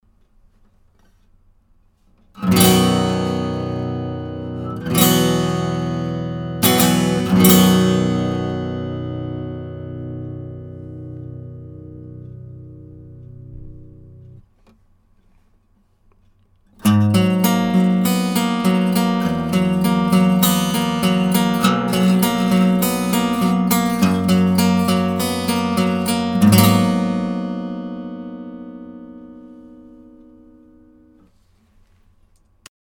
今回ピエゾを作って取り付ける Furch G23-SRCT です。
音の傾向としてはLakewood辺りに近いと思いますがFurchはローミッドがちょっと癖がある感じに濃ゆいです。
Rode NT2A :